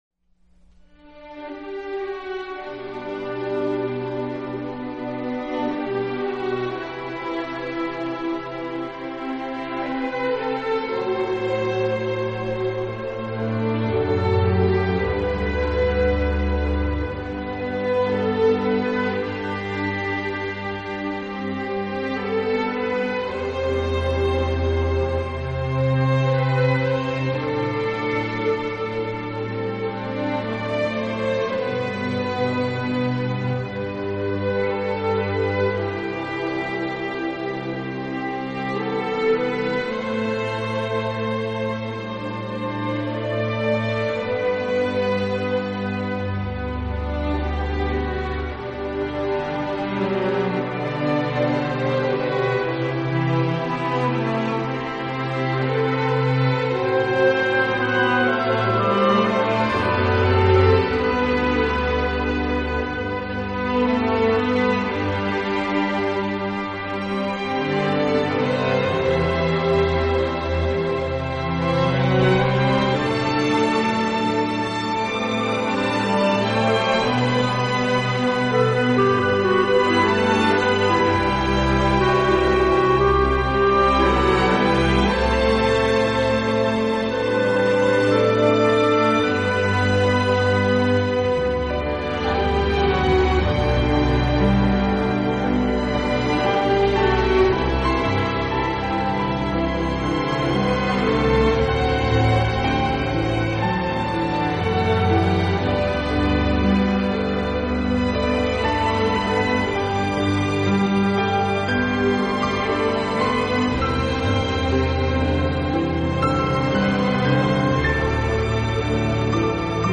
音乐类型：Newage